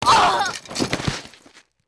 女死亡倒地－YS070511.wav
通用动作/01人物/04人的声音/死亡/女死亡倒地－YS070511.wav
• 声道 單聲道 (1ch)